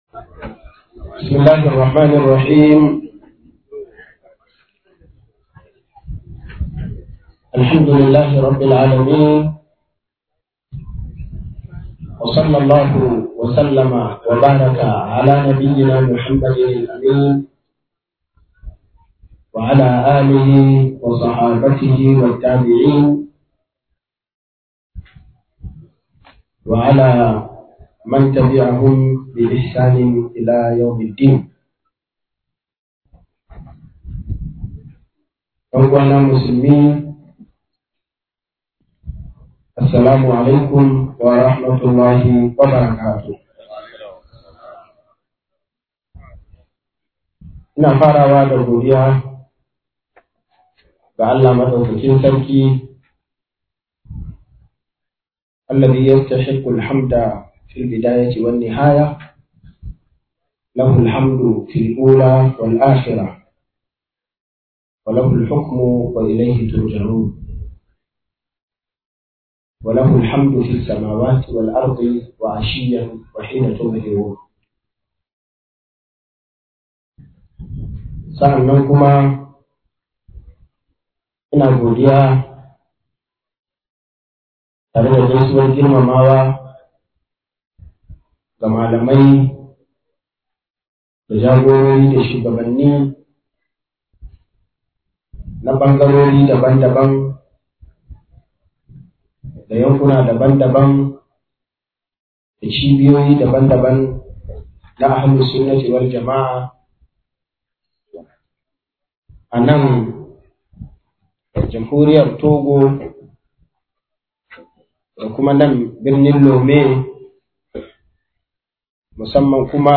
Kasuwanci a Musulunci - MUHADARA